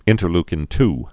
in·ter·leu·kin-2
(ĭntər-lkĭn-t)